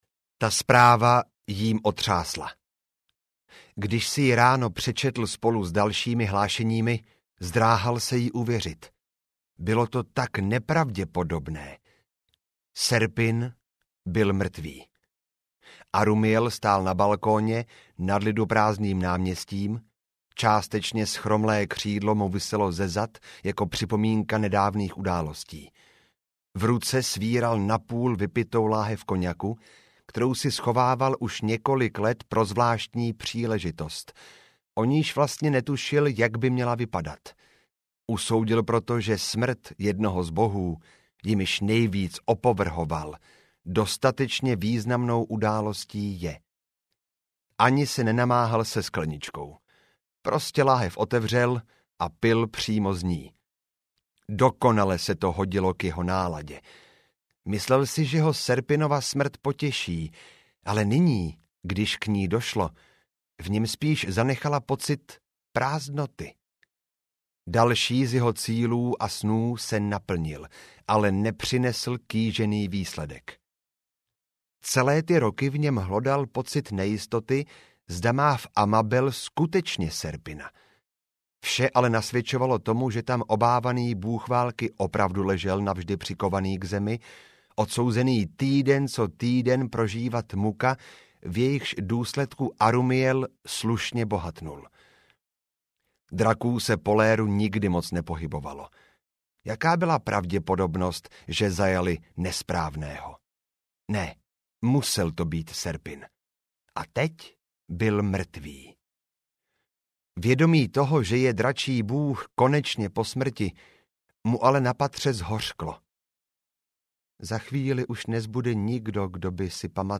Ukázka z audioknihy Oko bouře
Ve svém přednesu dokáže věty zakončit do tajemně zabarvených hlubin, které podporují atmosféru tohoto díla. Stejně tak ve vypjatých emotivních momentech, kterých není málo, dokáže vše procítěně přenést do tónu svých slov. Jeho plynulé tempo předčítání a melancholicky laděné předěly vás vtáhnou a nepustí stejně tak jako velmi lákavý obsah celé audioknihy.